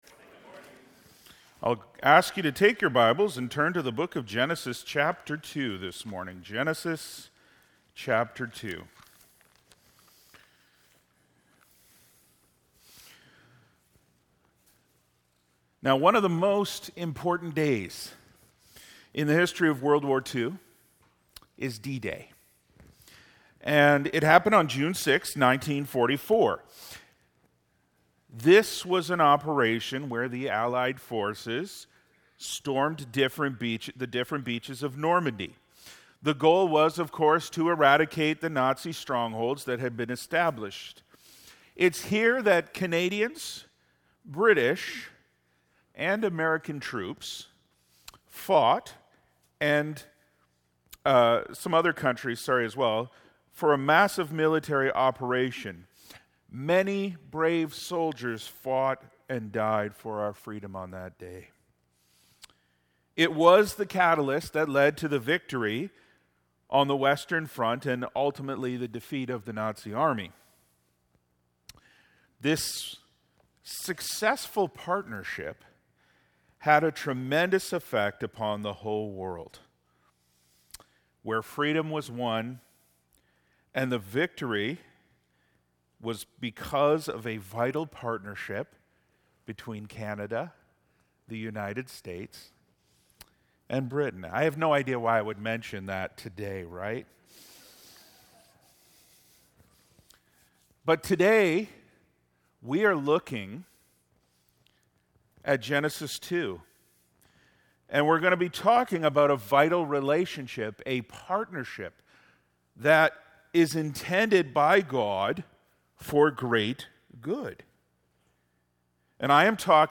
Sermons | Faith Baptist Church